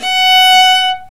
Index of /90_sSampleCDs/Roland - String Master Series/STR_Viola Solo/STR_Vla3 Arco nv
STR VIOLA 0J.wav